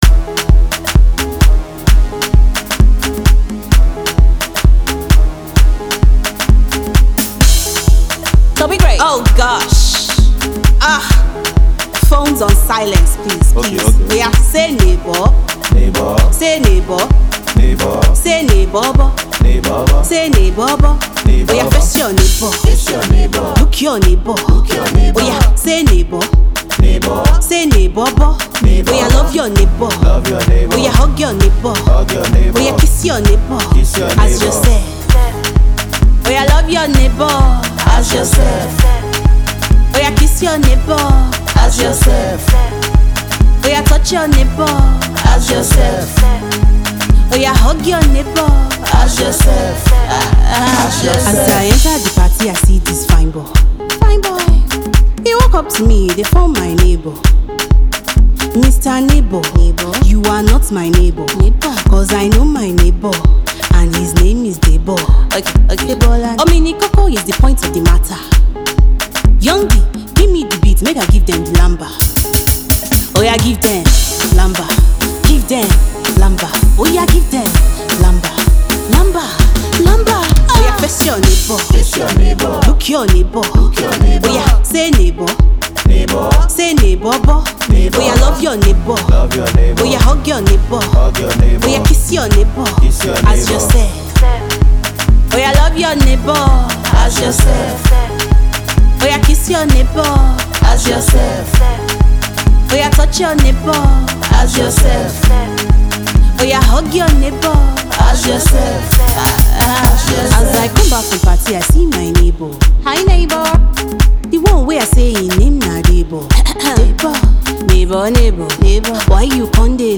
The uptempo song